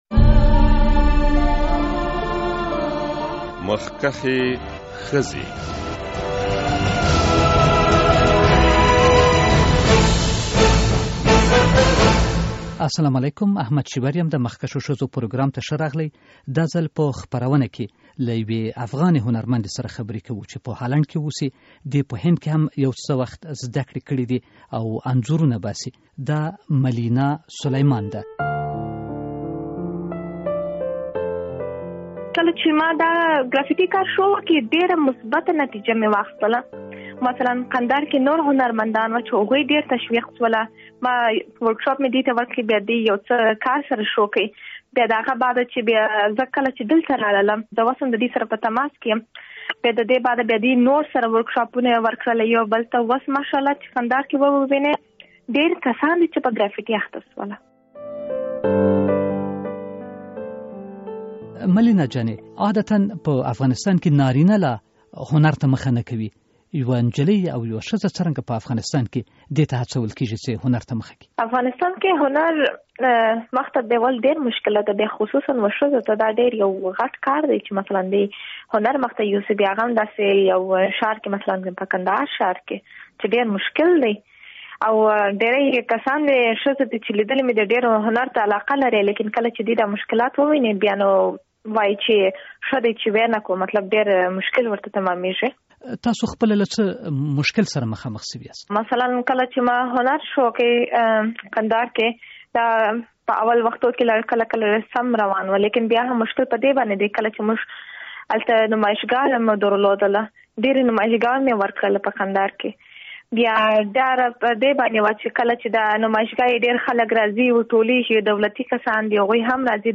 له یوې افغان انځورګرې سره خبرې